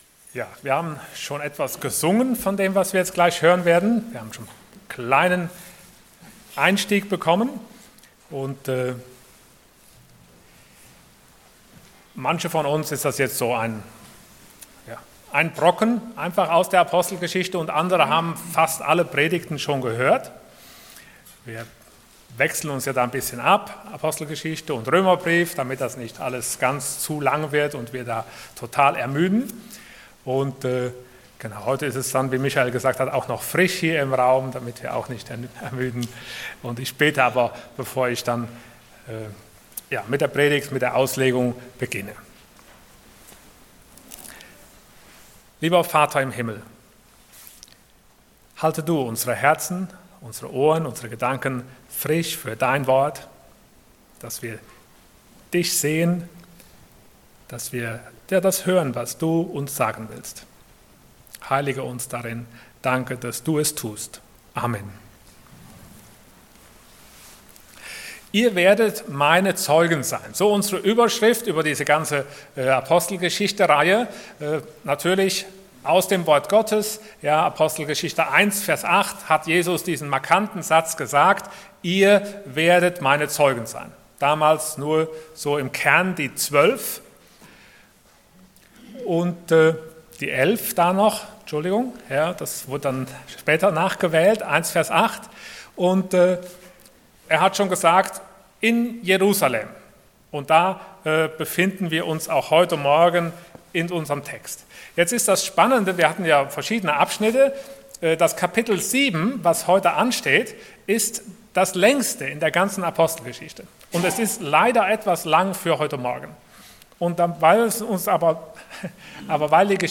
Passage: Acts 7:1-60 Dienstart: Sonntag Morgen